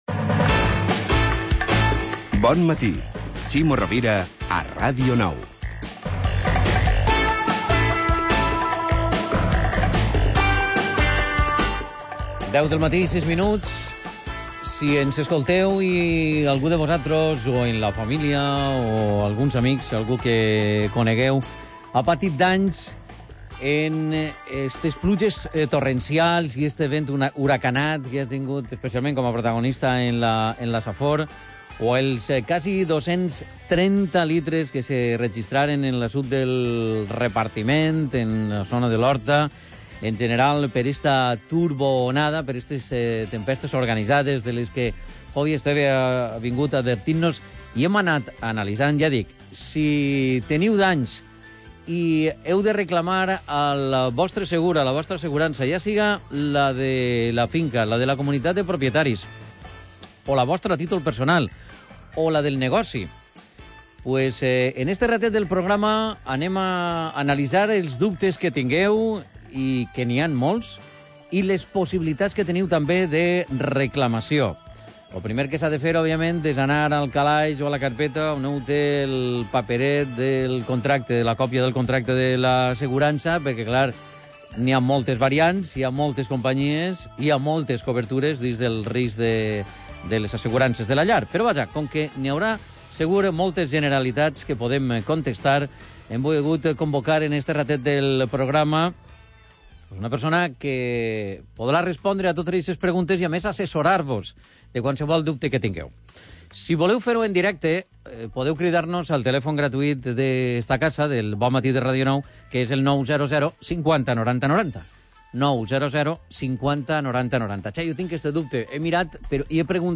Intervención de ACS-CV en Radio Nou por temporal de lluvias y viento